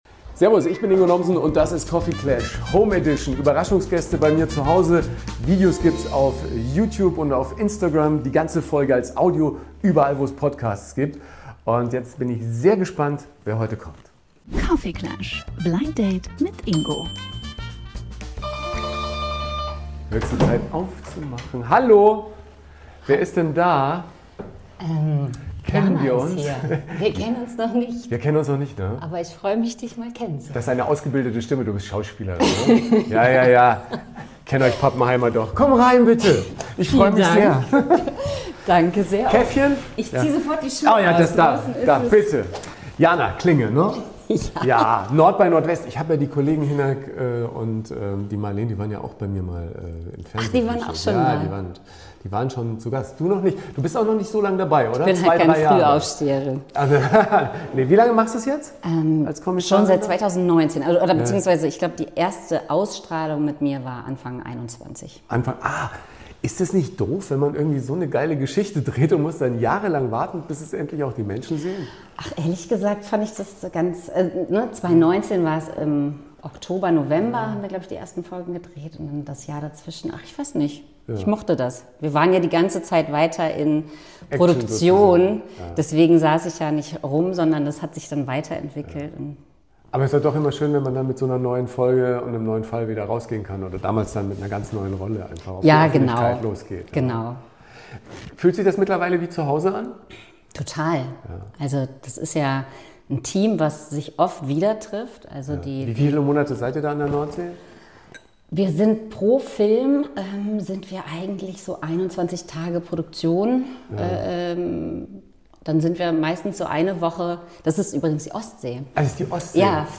Ein Gespräch über norddeutsche Geheimnisse, Berliner Bodenhaftung und die Kunst, zwischen Gans und Gewaltverbrechen zu pendeln.